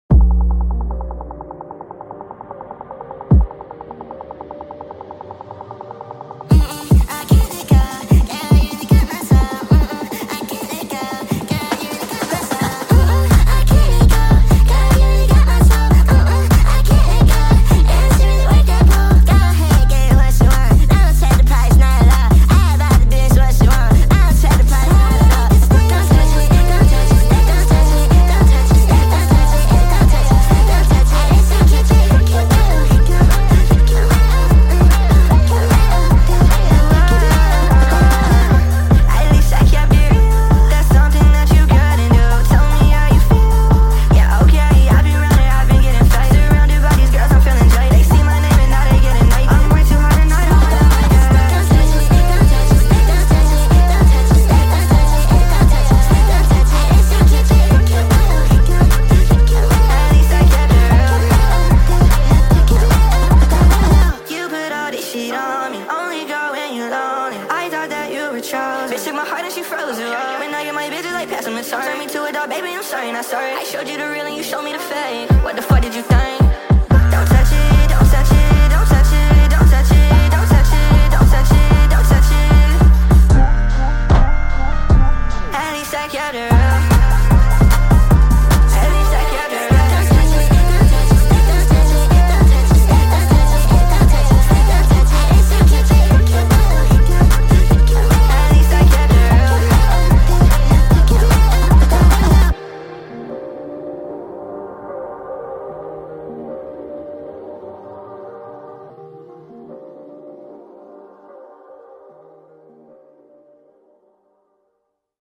Sped up and full song!